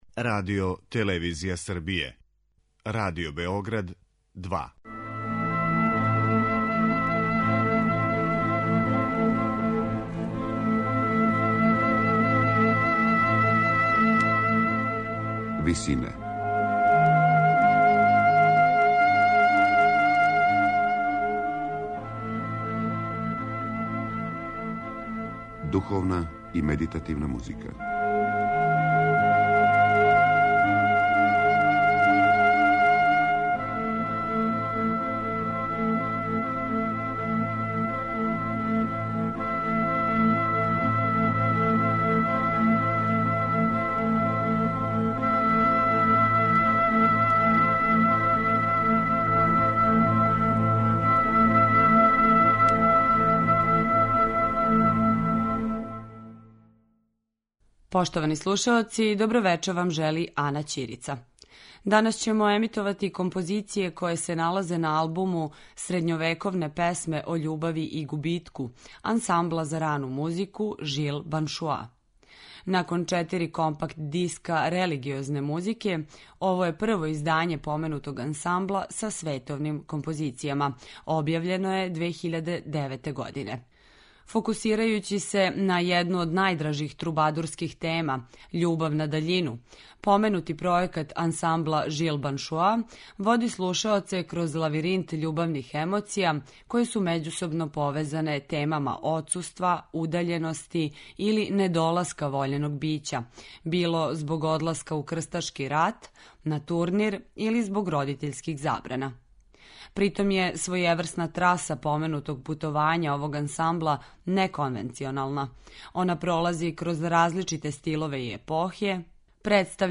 Емисија је посвећена духовној и медитативној музици